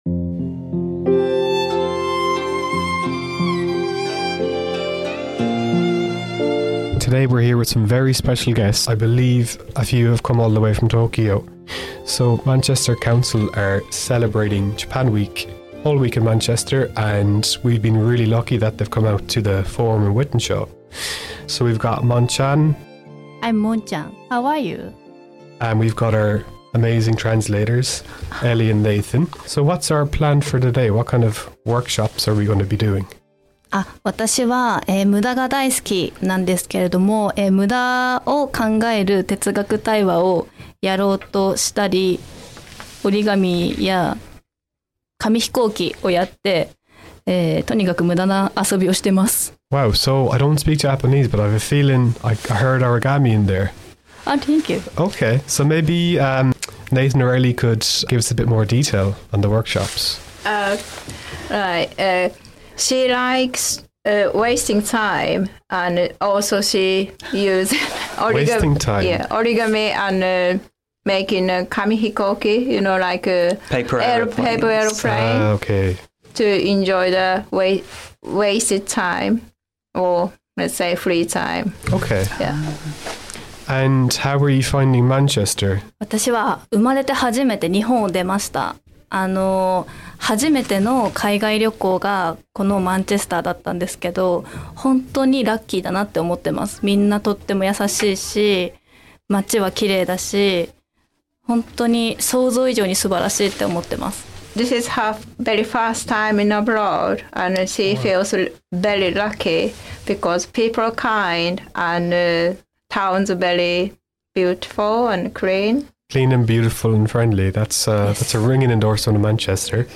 speaks to participants and people who came to the Wythenshawe Forum, celebrating Japan week across Manchester back in September 2025